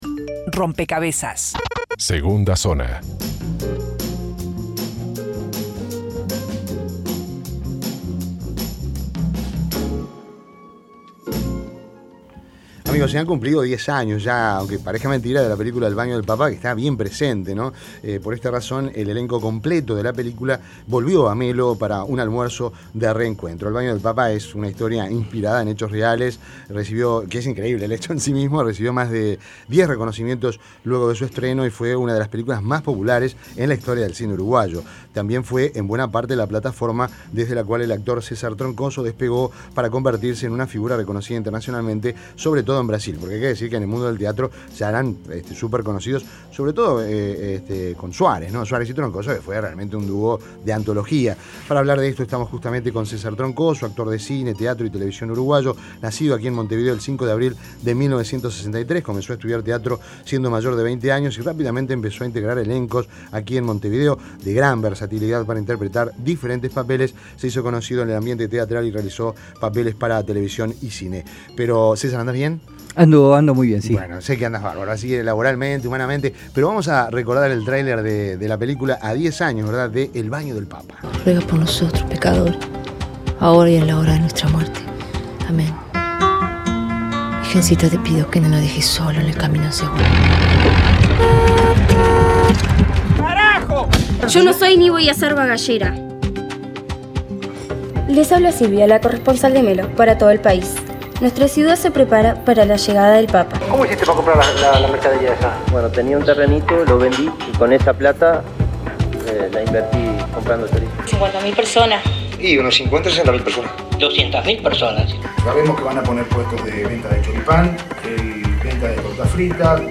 Entrevista en Rompkbzas Un actor en sus zapatos Imprimir A- A A+ César Troncoso visitó Melo, Cerro Largo, a 10 años del estreno de la película 'El baño del Papa'.